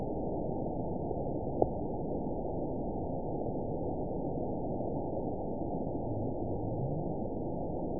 event 922740 date 03/24/25 time 13:23:52 GMT (2 months, 3 weeks ago) score 9.48 location TSS-AB02 detected by nrw target species NRW annotations +NRW Spectrogram: Frequency (kHz) vs. Time (s) audio not available .wav